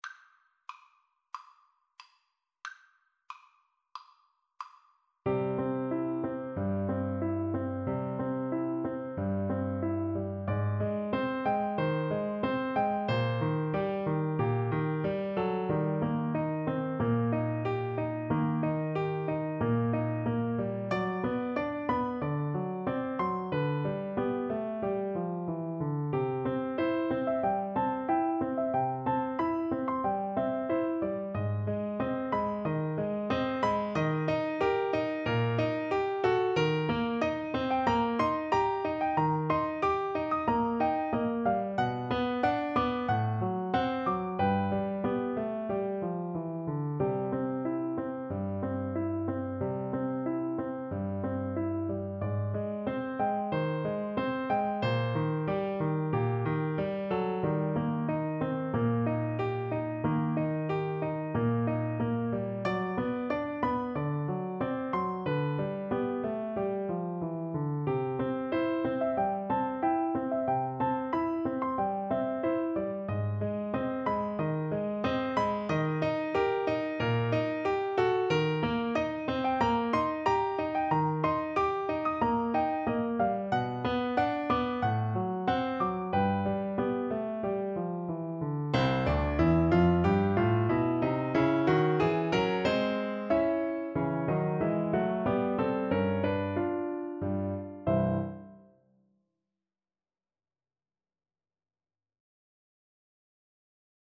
Andantino =92 (View more music marked Andantino)